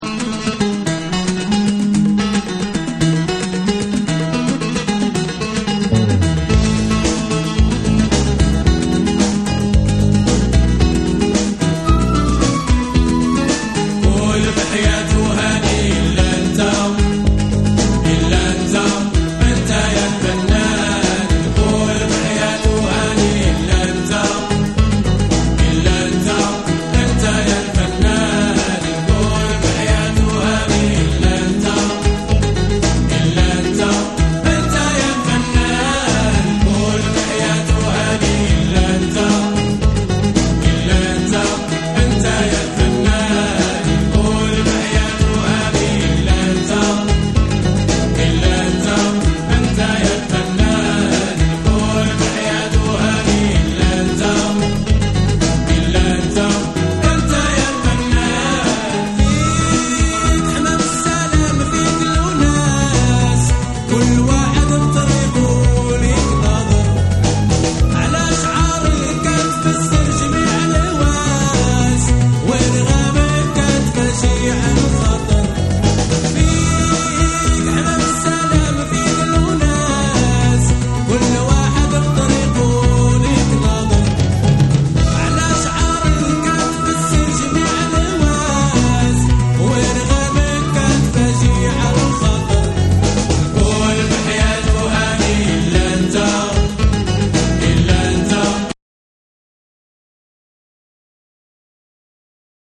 中東系の怪しげな旋律をダンス・ビートに落とし込んだ楽曲を多数収録。程よく牧歌的な雰囲気も漂う
NEW WAVE & ROCK / ORGANIC GROOVE